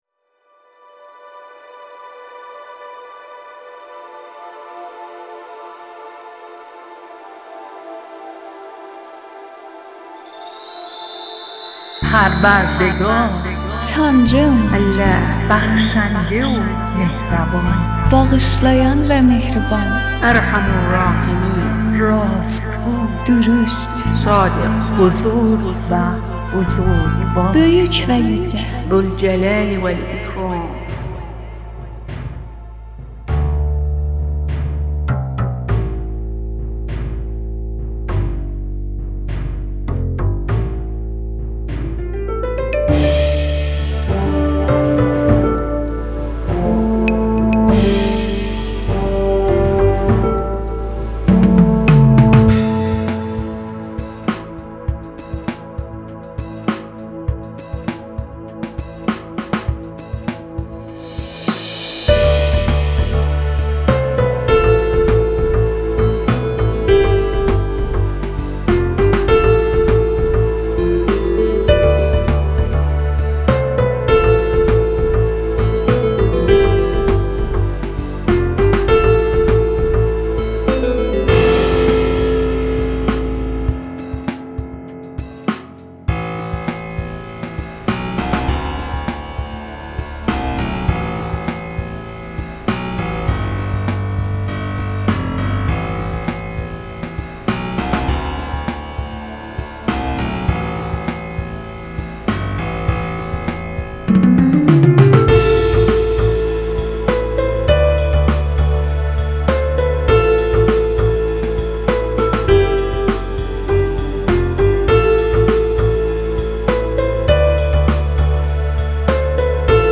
موسیقی بی کلام
Instrumental Music